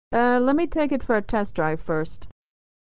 Unstressed 'for' is reduced = /fər/